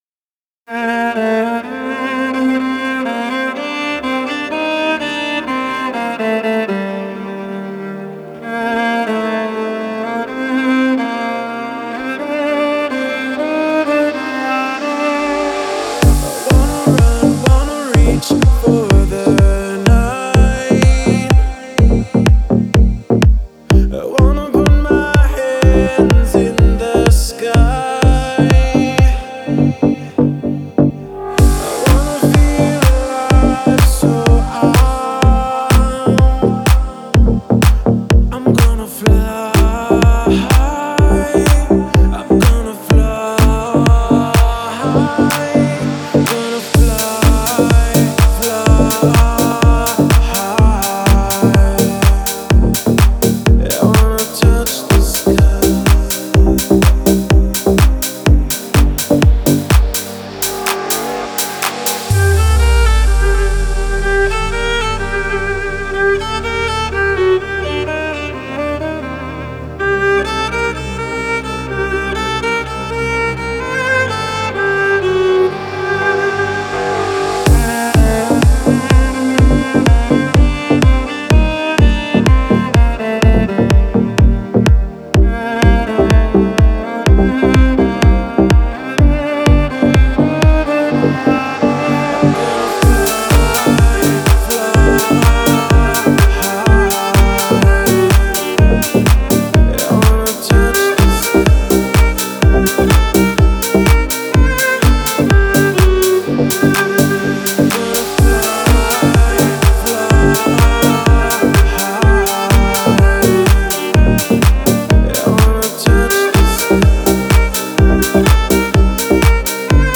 это трек в жанре электронной музыки